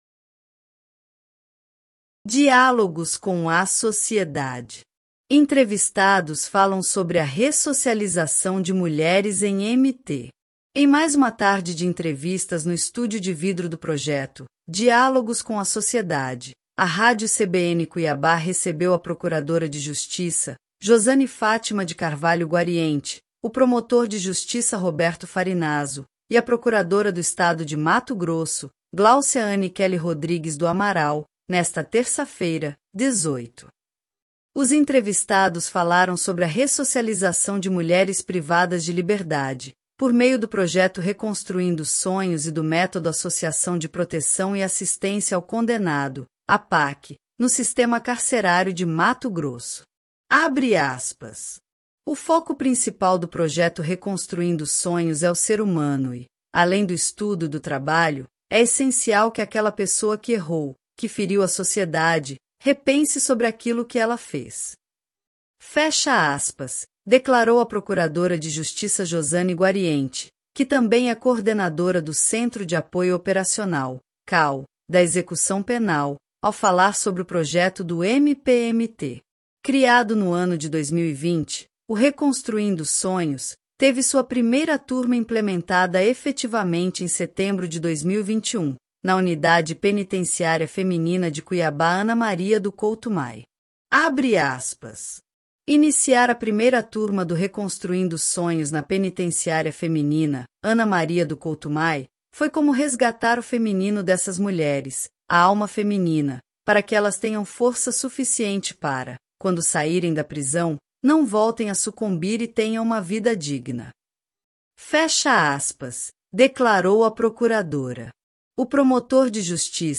Em mais uma tarde de entrevistas no estúdio de vidro do projeto “Diálogos com a Sociedade”, a Rádio CBN Cuiabá recebeu a procuradora de Justiça Josane Fátima de Carvalho Guariente, o promotor de Justiça Roberto Farinazzo e a procuradora do Estado de Mato Grosso Glaucia Anne Kelley Rodrigues do Amaral, nesta terça-feira (18).